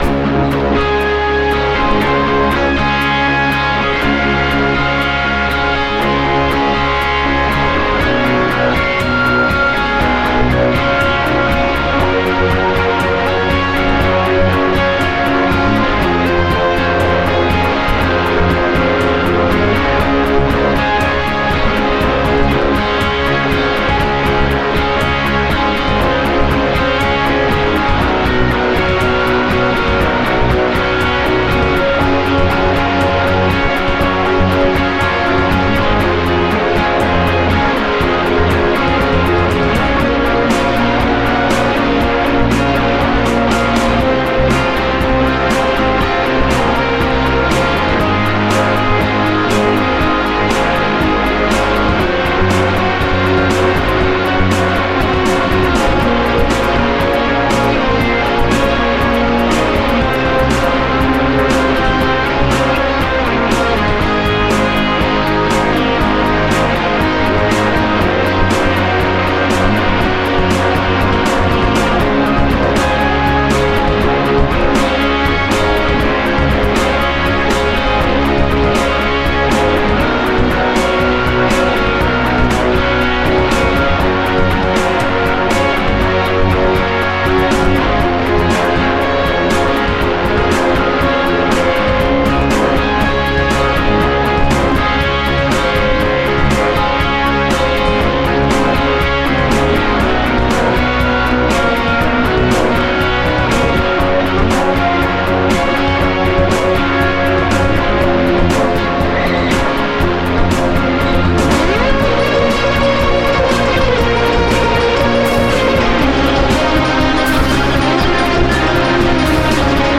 - 2004 ] Space piano [ buzz song with sampled piano - 2004 ] Spirit of light [ guitars, saturation, drums, bass...